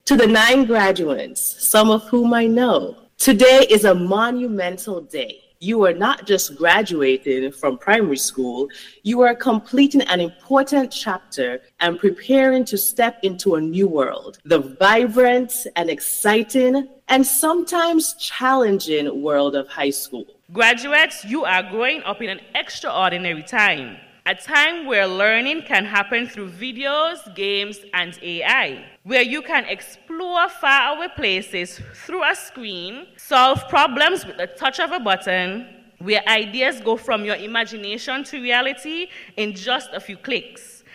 Under the theme: “Embracing the Digital Future; a Bridge to Endless Possibilities”, the St. James Primary School held its graduation ceremony on Friday, July 4th, 2025 at the Nevis Performing Arts Center (NEPAC).
Featured Address